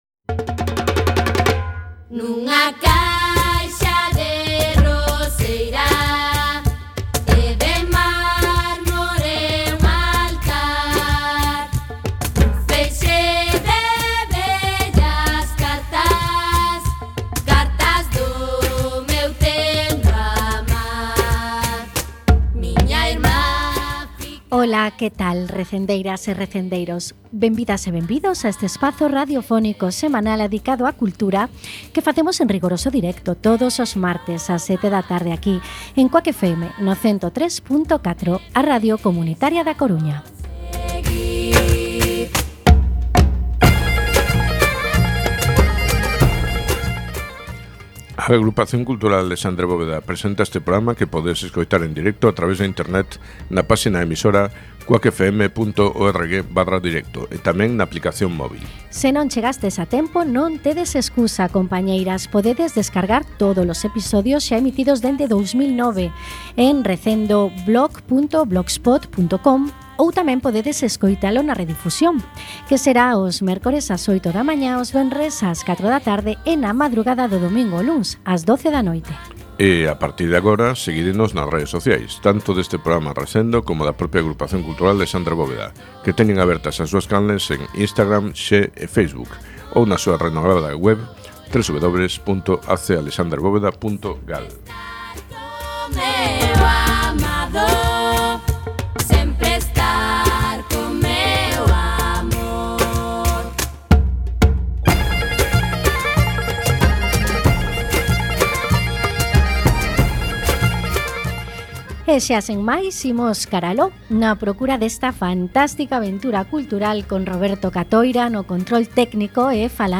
16x15 Entrevista